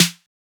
drum-hitnormal.wav